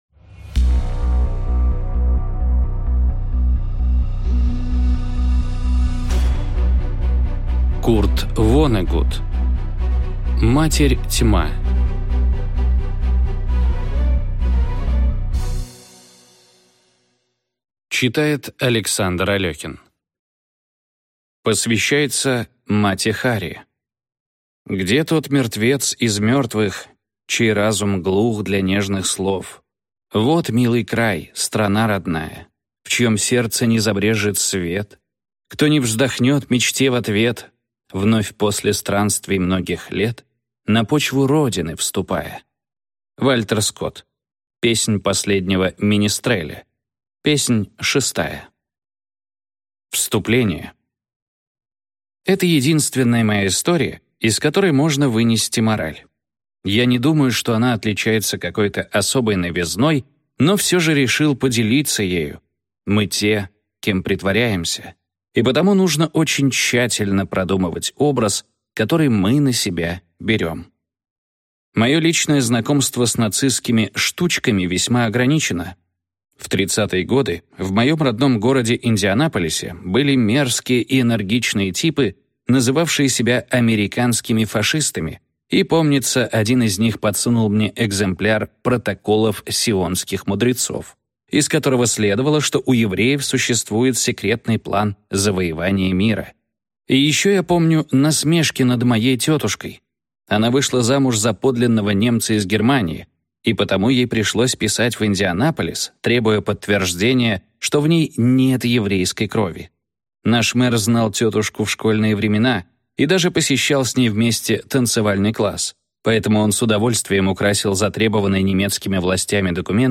Аудиокнига Матерь Тьма | Библиотека аудиокниг